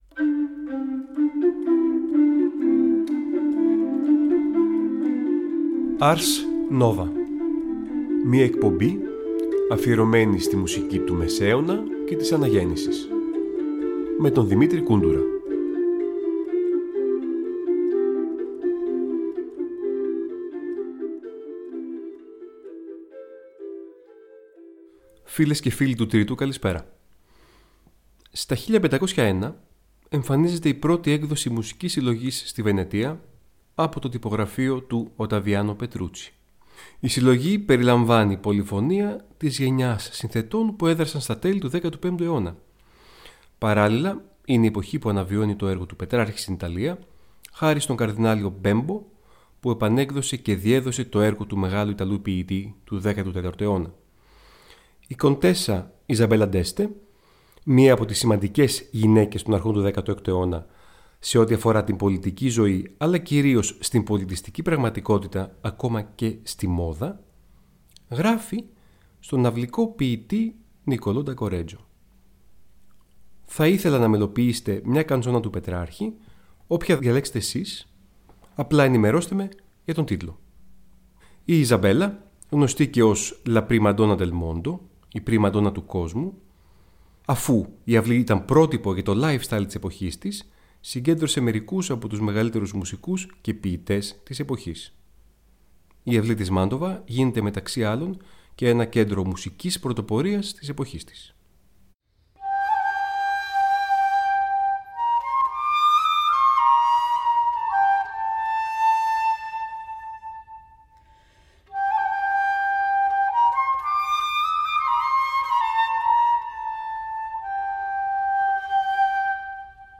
Οι Μουσικοί Θησαυροί του Μεσαίωνα και της Αναγέννησης
Νέα ωριαία μουσική εκπομπή του Τρίτου Προγράμματος που μεταδίδεται κάθε Τρίτη στις 19:00.